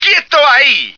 flak_m/sounds/male2/est/M2staydown.ogg at ac4c53b3efc011c6eda803d9c1f26cd622afffce